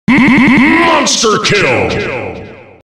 Звук смерти монстра - Альтернативная версия